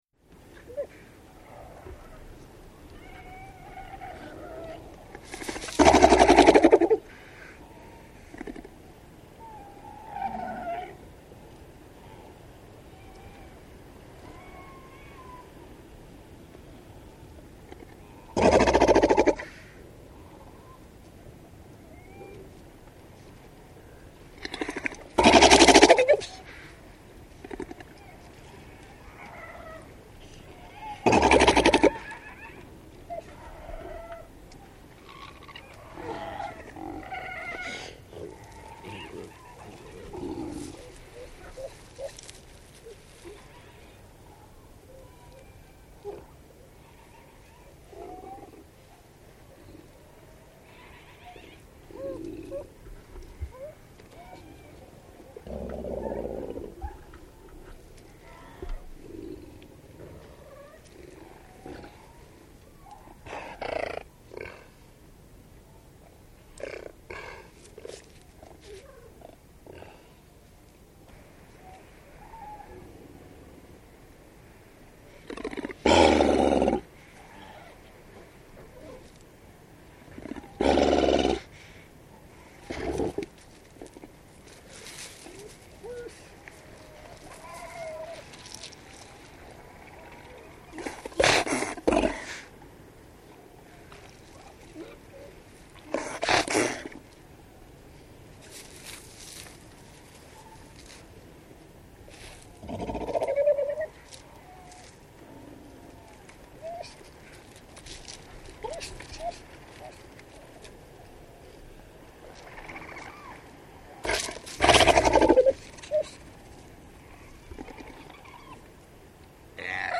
В коллекции — рычание, кряхтение и другие характерные голосовые реакции этих морских животных.
Звуки Антарктики в компании морских котиков